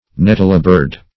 Nettlebird \Net"tle*bird`\, n. (Zool.)